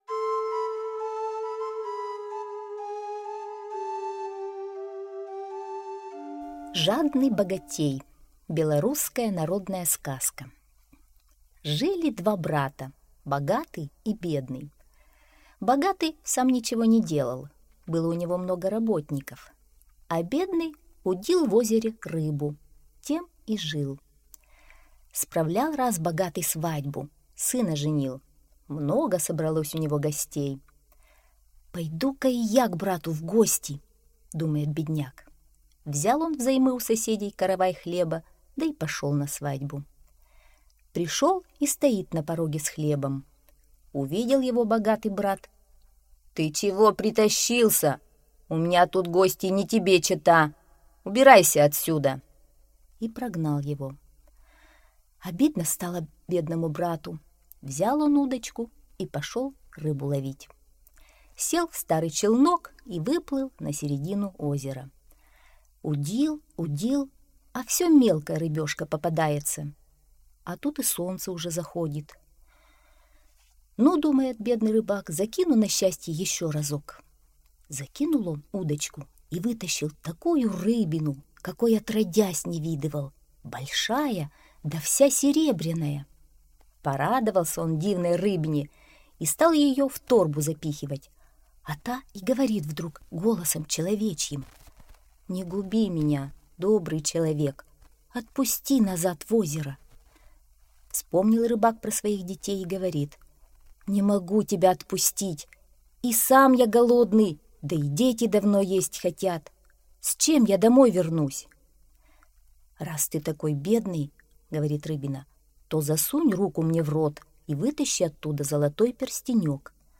Жадный богатей — аудиосказка.